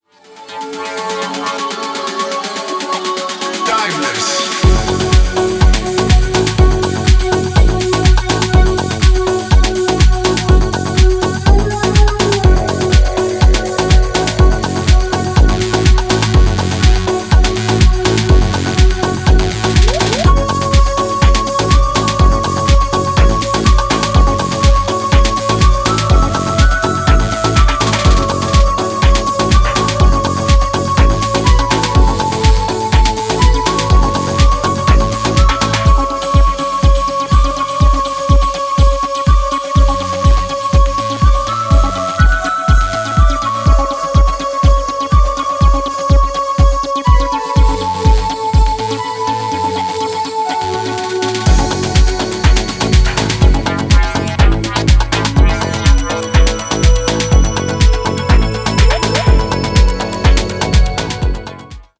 ダスティなベースラインと昇天に持っていく主旋律のシンセワークが対比したディープ・ハウス